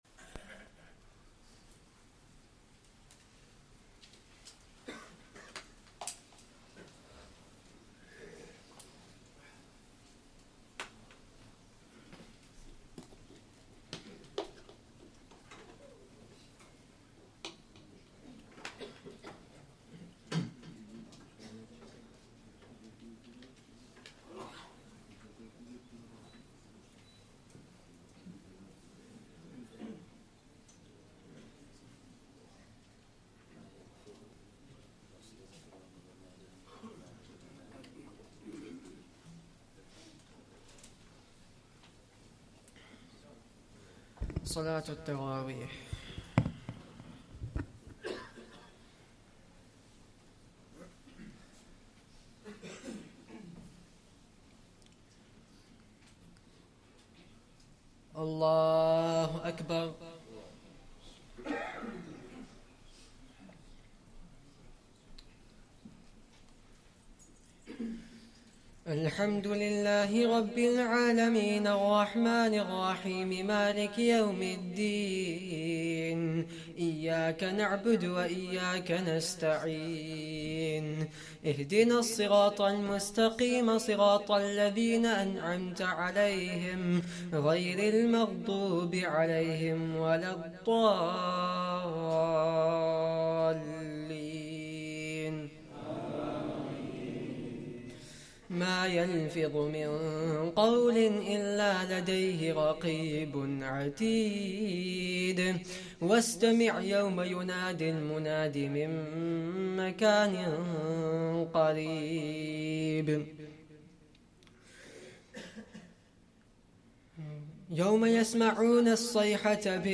2nd Tarawih prayer - 25th Ramadan 2024